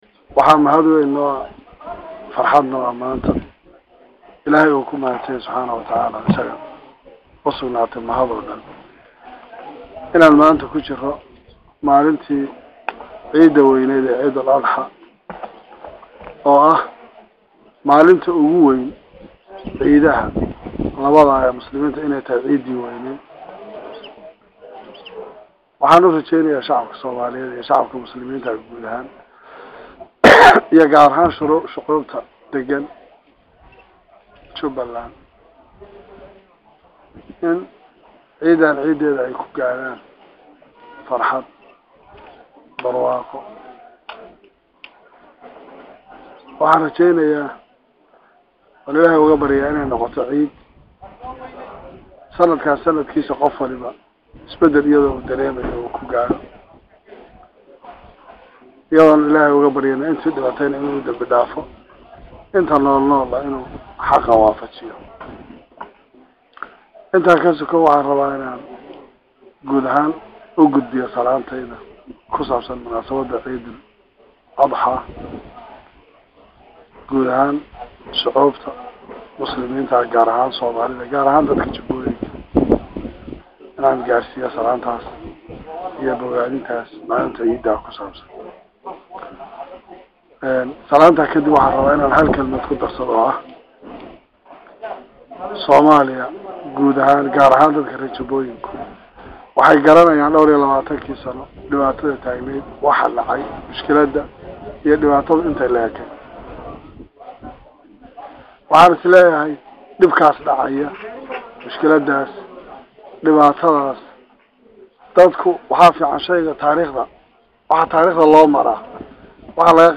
Madaxwaynaha maamulka jubba Oo boqolal  dad ah kula hadlayay xarunta madaxtooyada oo uu salada ku tukaday ayaa sheegay  in uu shacabka soomaaliyeed ugu hambalyaynayo munaasabada ciida ah sidoo kale waxaa dadkii halkiis iskugu yimid u sheegay in la’iscafiyo.